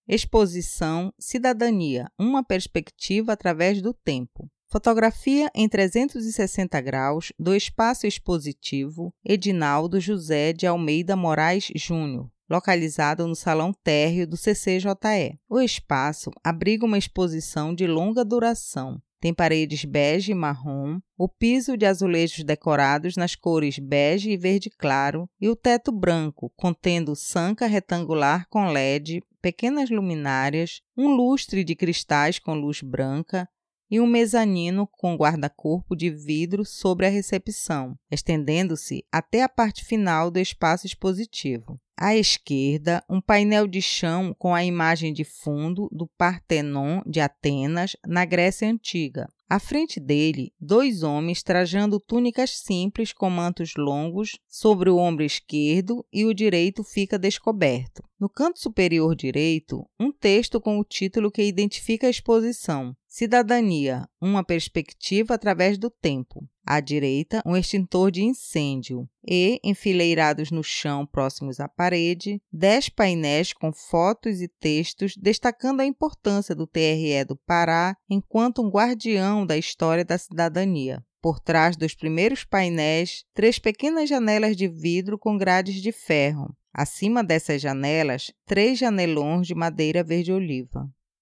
Exposição Cidadania Uma Perspectiva Através do Tempo audiodescrição